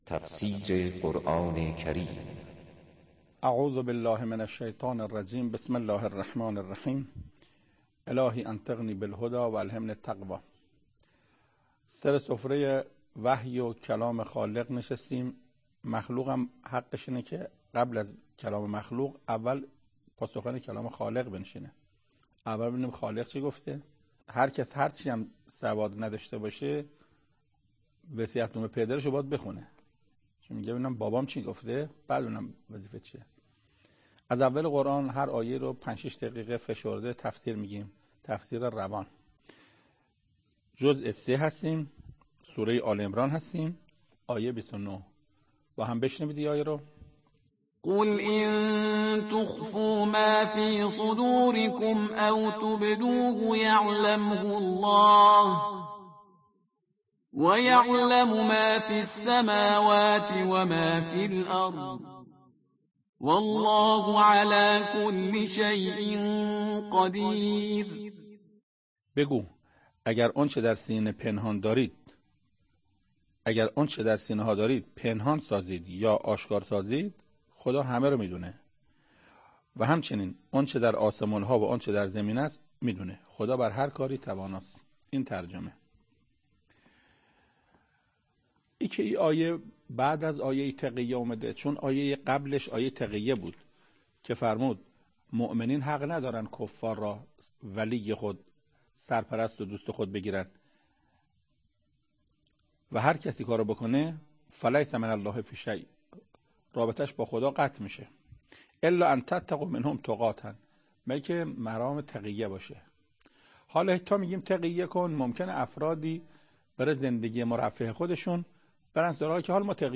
تفسیر صوتی